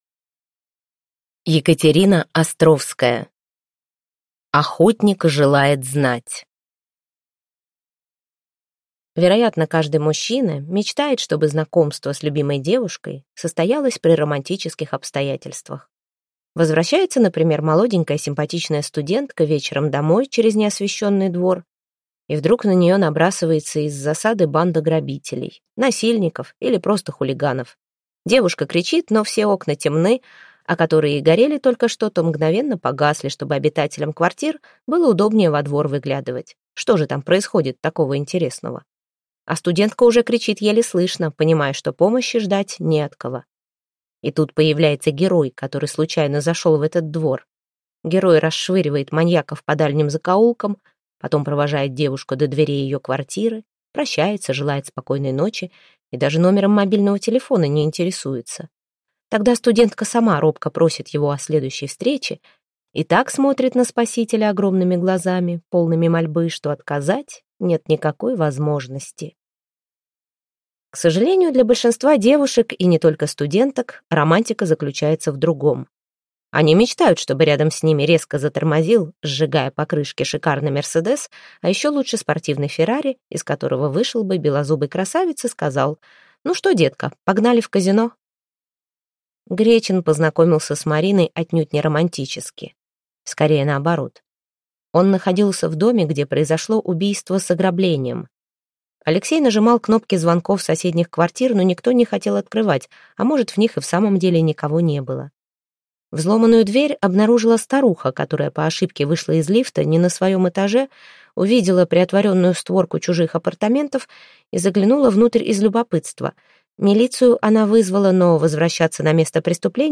Аудиокнига Охотник желает знать | Библиотека аудиокниг